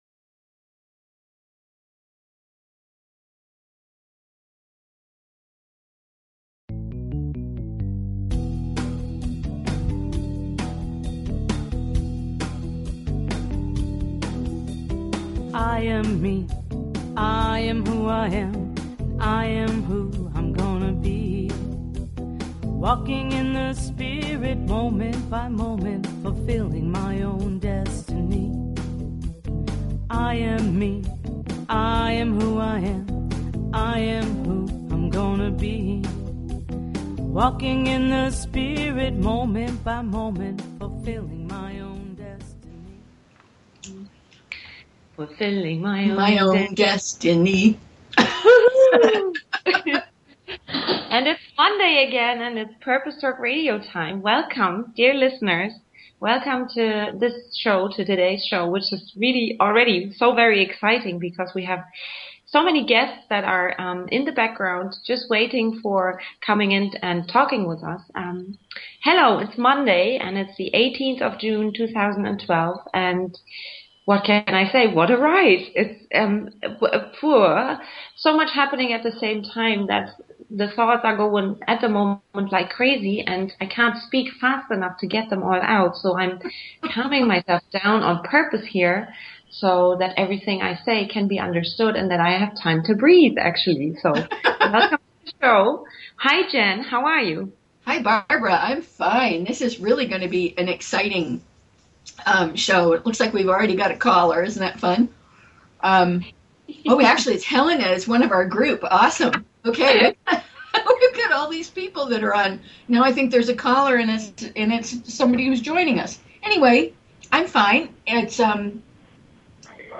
Talk Show Episode, Audio Podcast, Purpose_Talk_Radio and Courtesy of BBS Radio on , show guests , about , categorized as